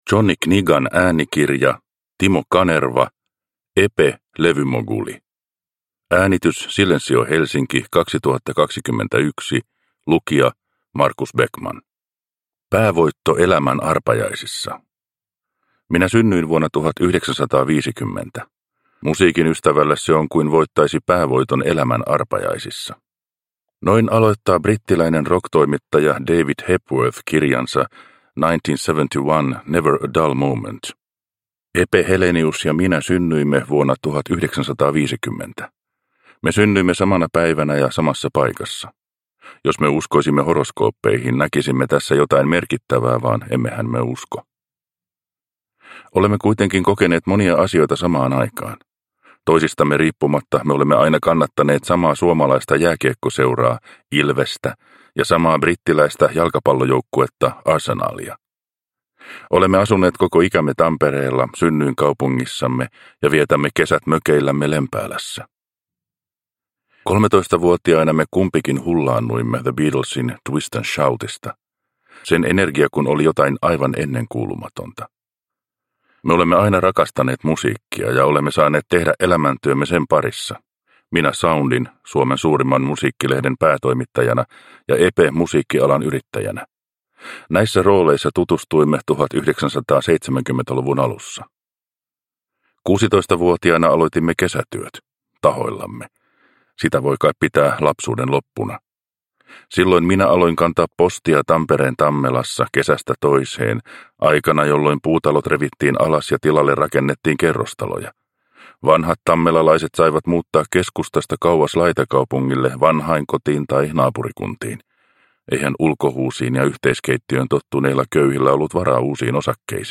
Epe – Ljudbok – Laddas ner